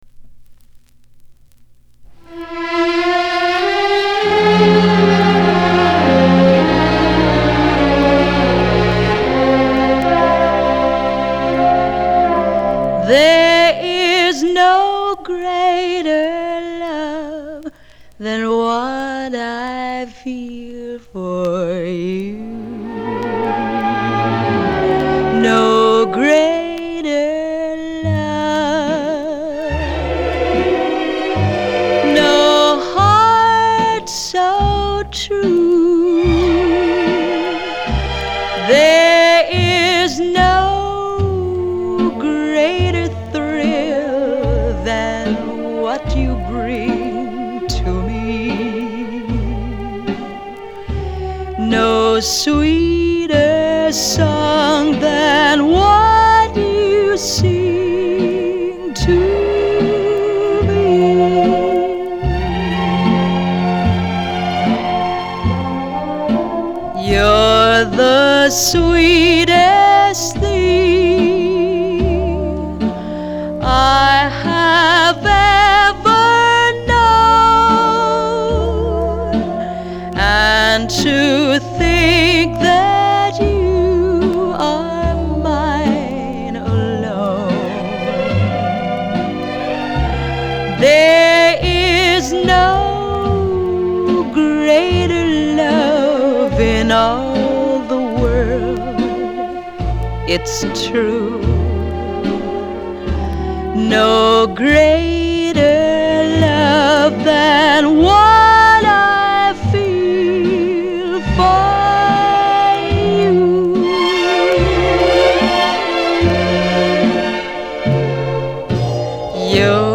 형식:레코드판, LP, Album, Mono
장르:Pop 1955.